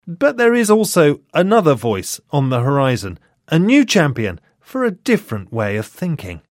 【英音模仿秀】发达国家的紧缩政策 听力文件下载—在线英语听力室